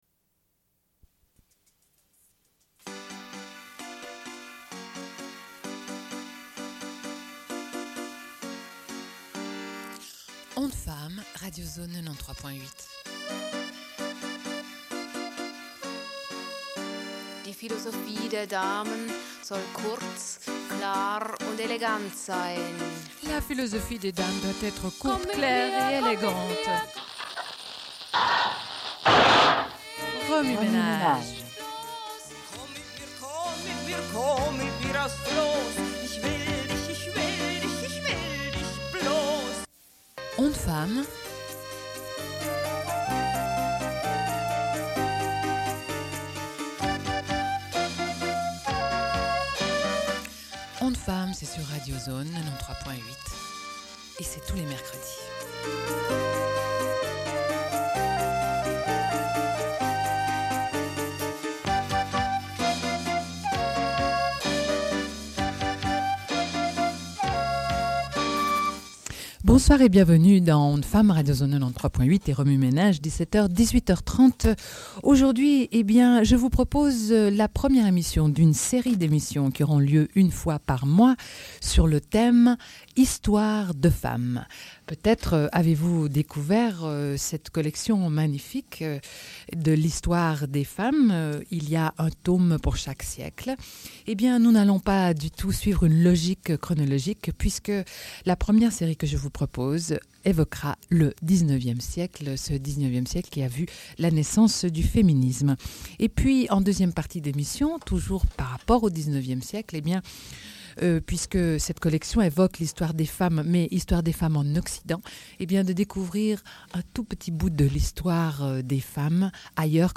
Une cassette audio, face A31:20